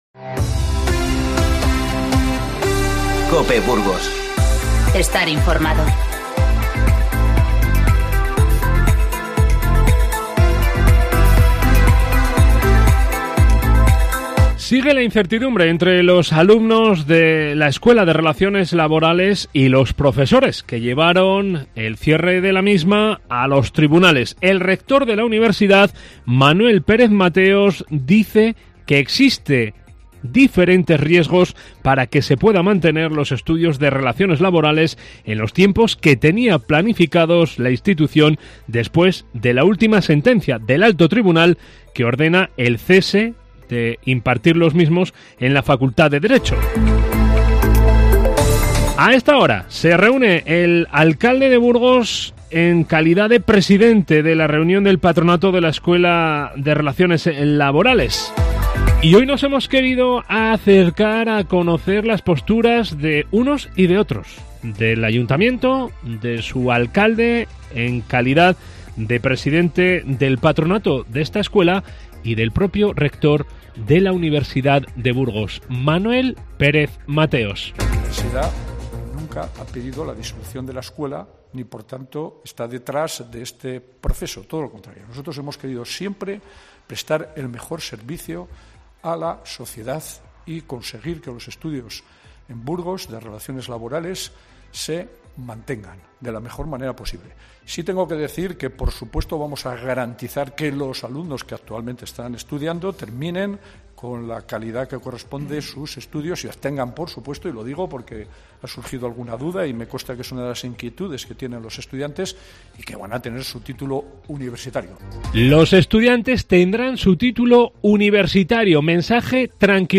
Informativo 15-01-20